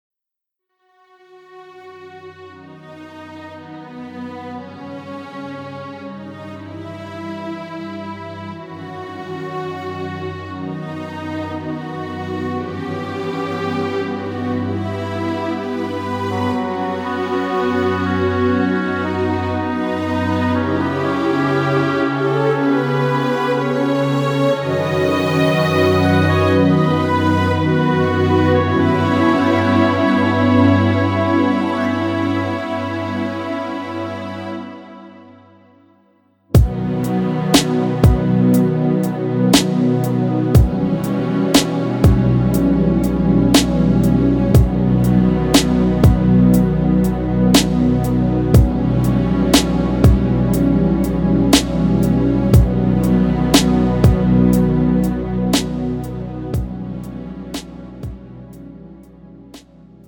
음정 -1키
장르 pop 구분 Pro MR